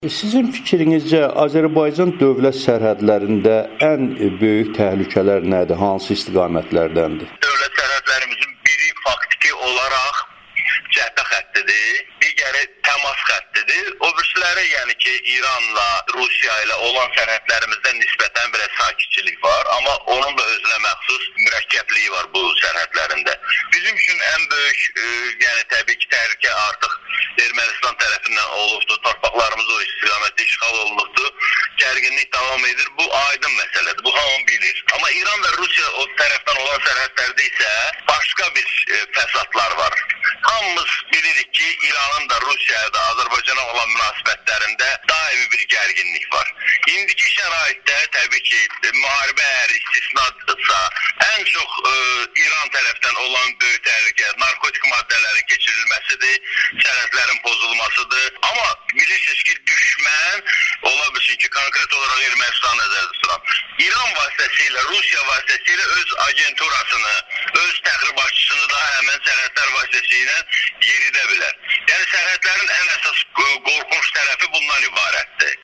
ekspert